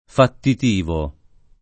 [ fattit & vo ]